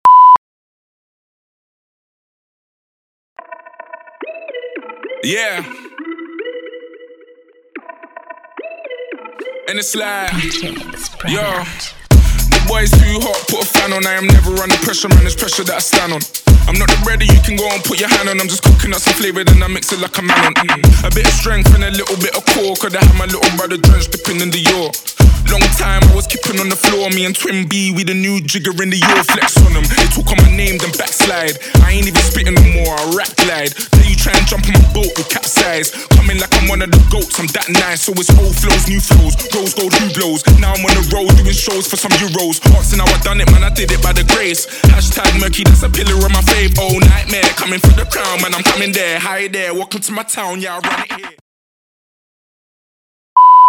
• der Piepton am Anfang und Ende des Tracks muss deutlich im Video zu hören sein
• die Musik startet ca. 3 Sekunden nach dem Piepton; ca. 3 Sekunden nach Ende des Tracks
hört ihr erneut einen Piepton
WARNUNG: Der Ton am Anfang und Ende des Videos ist sehr laut.